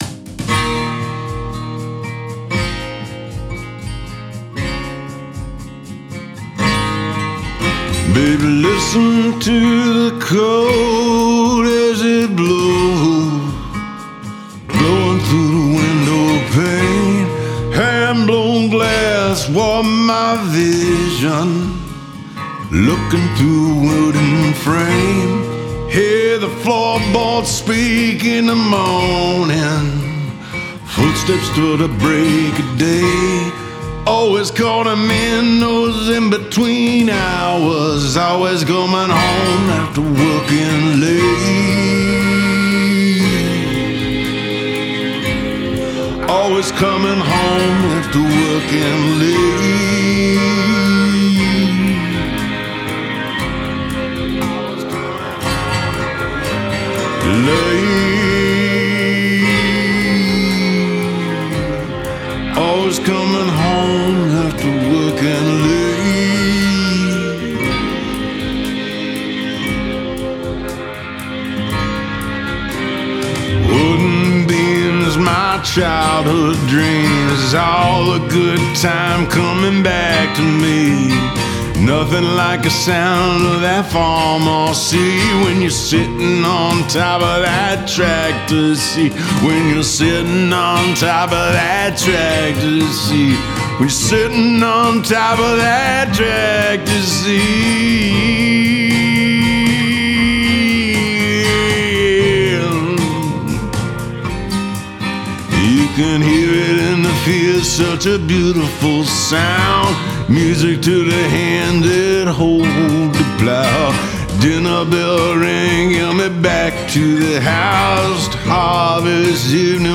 Genre: Rock, Jam Band, Americana, Roots.